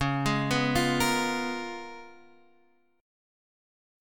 C#13 Chord